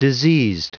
Prononciation du mot diseased en anglais (fichier audio)
Prononciation du mot : diseased